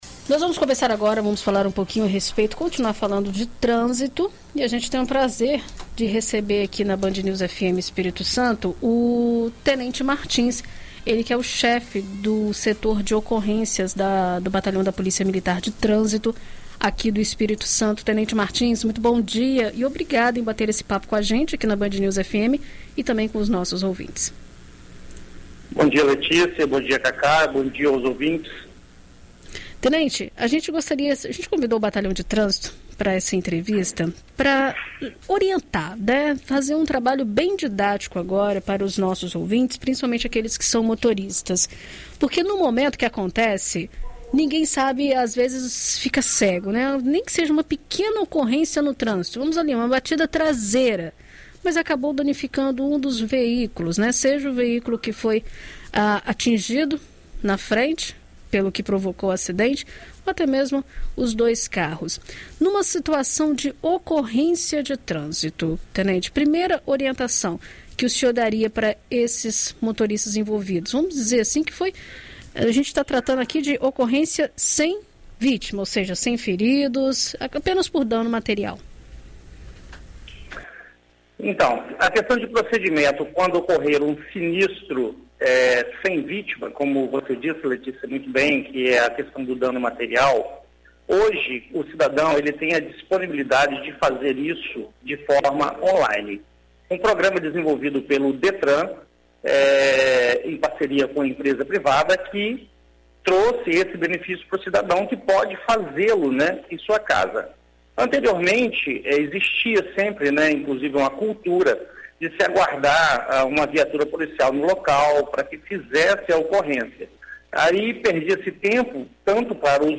Em entrevista à BandNews FM Espírito Santo nesta quinta-feira (13)